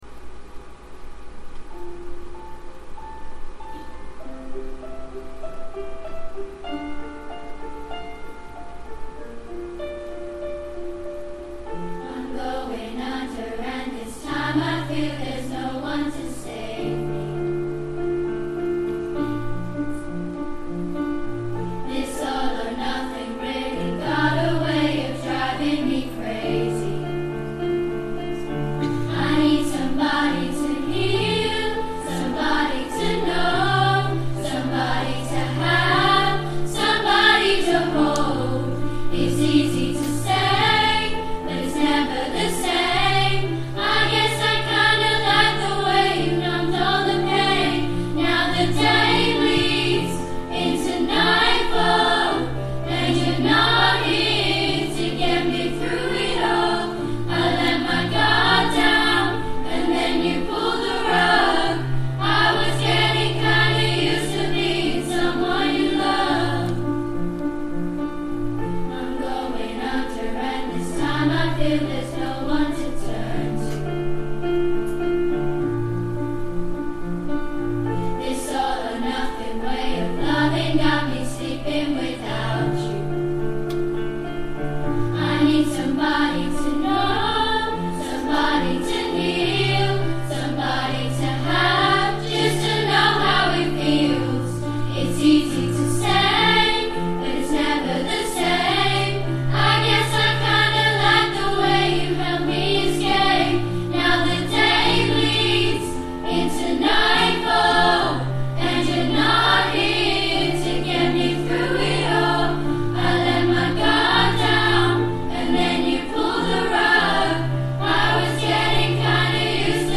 Junior Choir